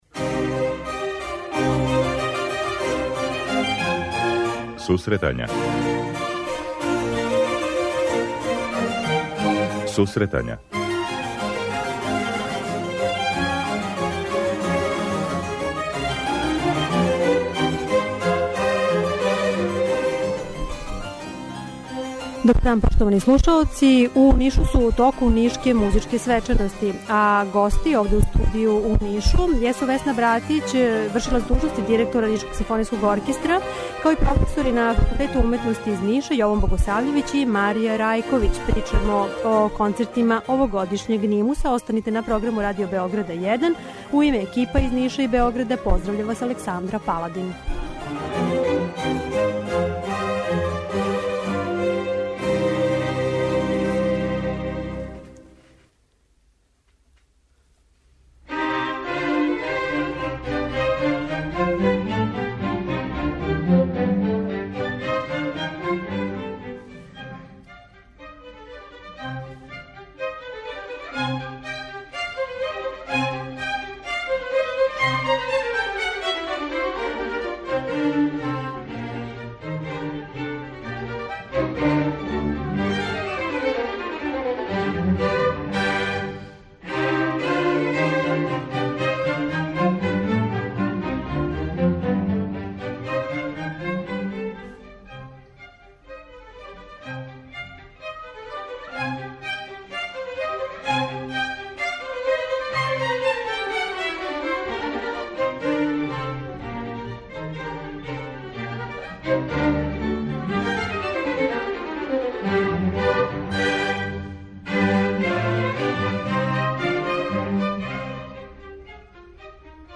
Емисија се реализује директно из Ниша, у коме се одржава фестивал 'Нишке музичке свечаности'.